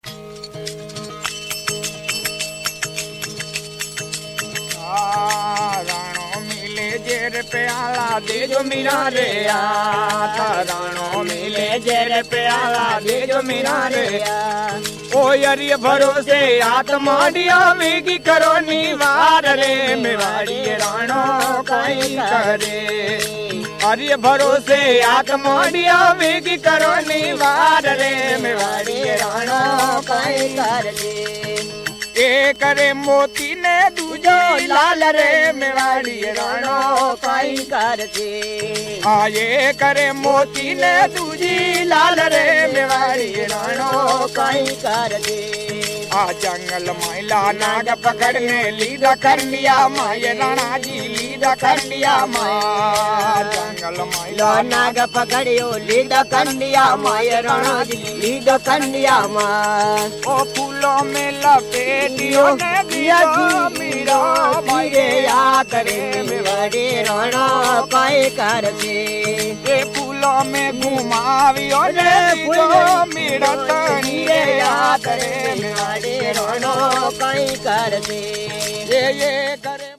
Bhajan: Mira bhajan, "Meware Rana Koi Karate"
Mira bhajan sung by an unidentified jogi who accompanies himself on a tandura (a spiked, long-necked lute) and khartal (small cymbals set in rectangular wooden frames), and is accompanied by another jogi who plays mañjira (small, hand-held cymbals) and a man who joins in the singing on repeats as a congregation would if it were performed communally.